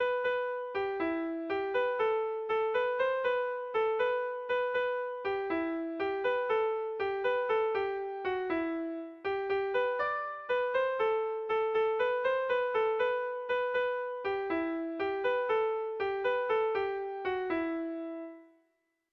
Erlijiozkoa
Zortziko txikia (hg) / Lau puntuko txikia (ip)
ABDB